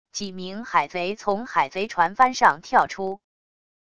几名海贼从海贼船帆上跳出wav音频